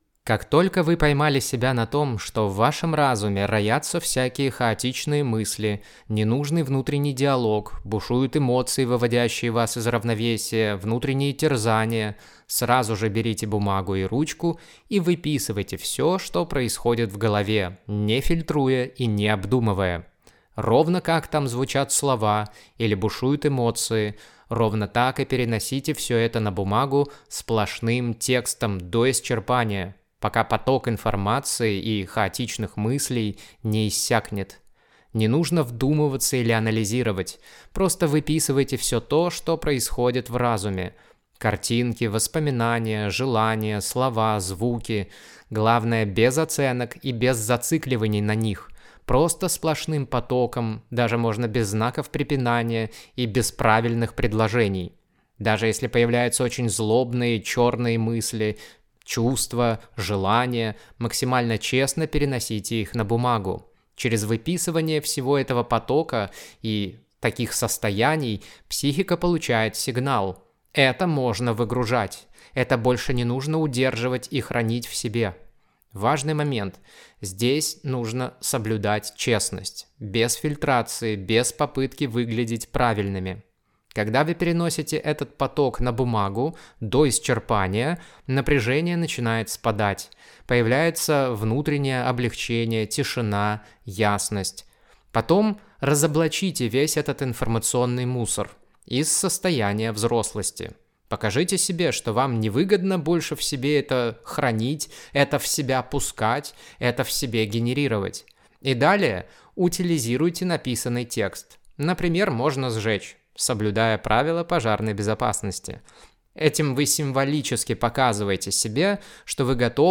Практика очищения разума от негативных мыслей в аудио версии: